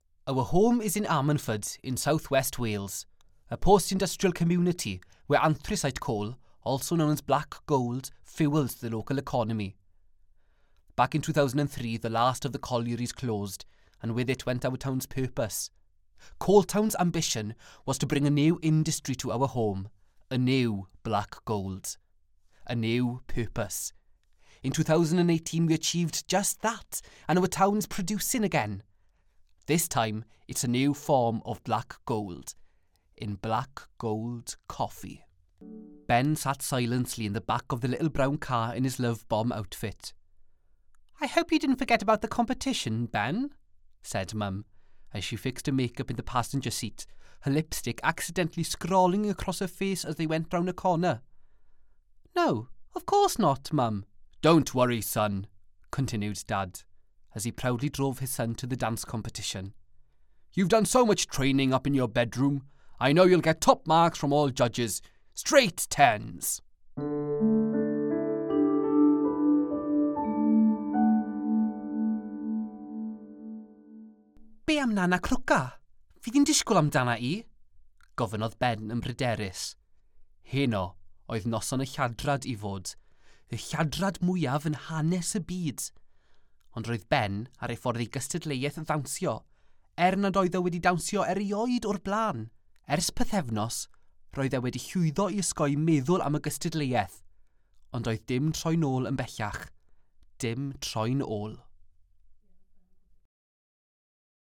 Welsh, Male, 20s-30s